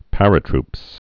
(părə-trps)